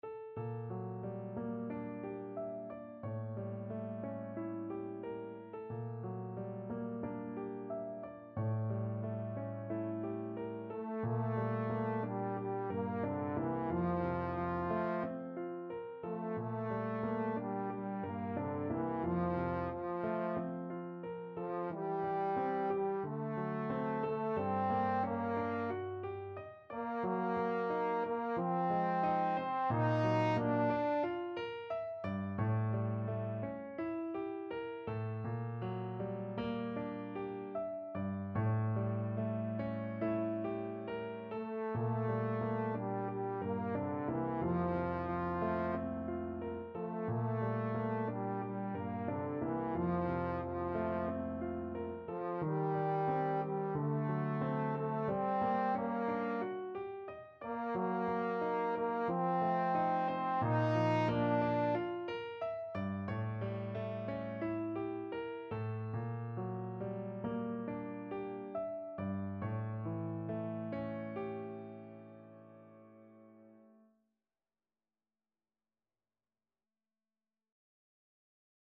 Trombone version
Key: F major (Sounding Pitch)
Time Signature: 2/4
Tempo Marking: ~ = 45 Langsam, zart